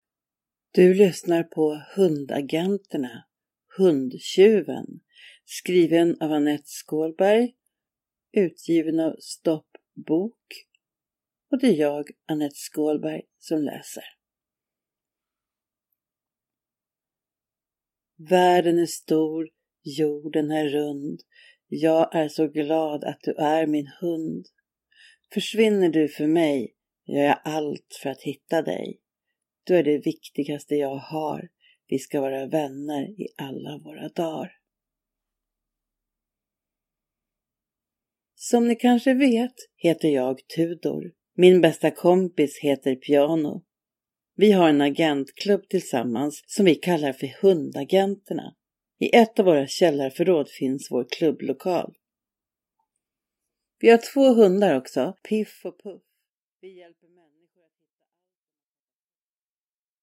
Hundagenterna HUNDTJUVEN – Ljudbok